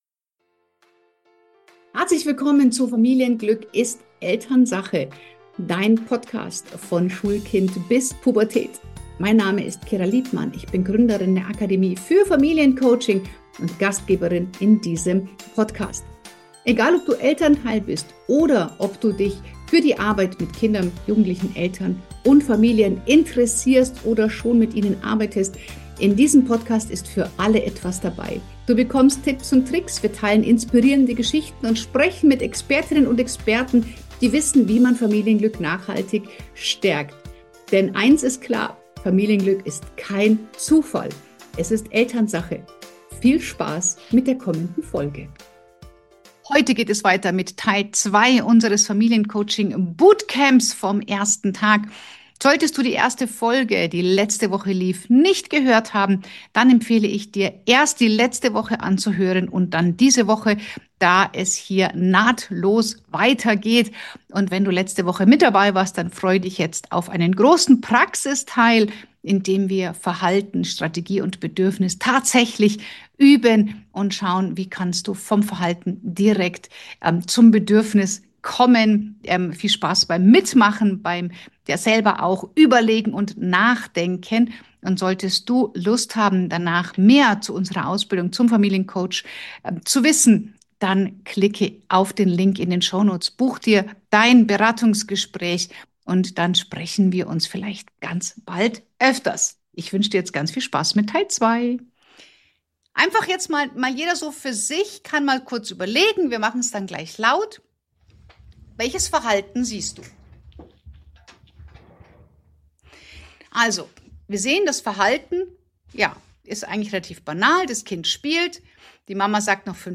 Beschreibung vor 18 Stunden Diese Folge ist die Fortsetzung von Tag 1 unseres Live-Webinars – und hier geht es um den entscheidenden Unterschied: Verstehen ist das eine.